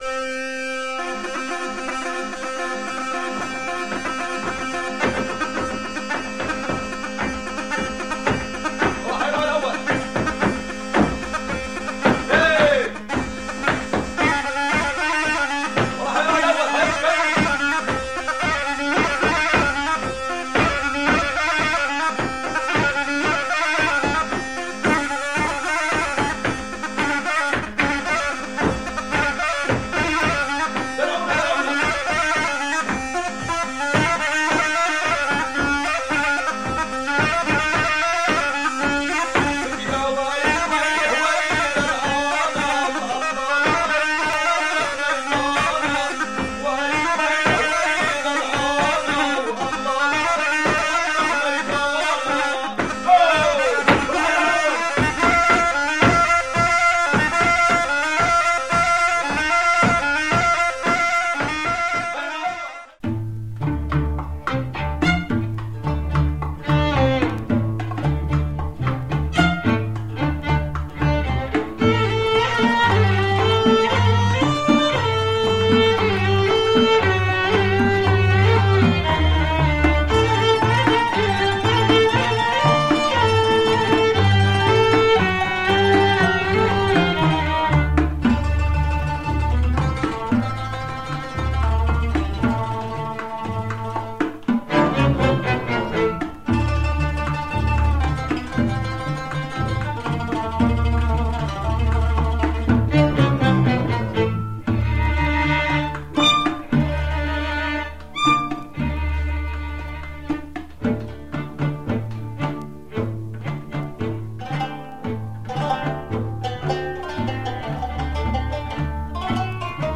Surprising 1960's Lebanese folk album.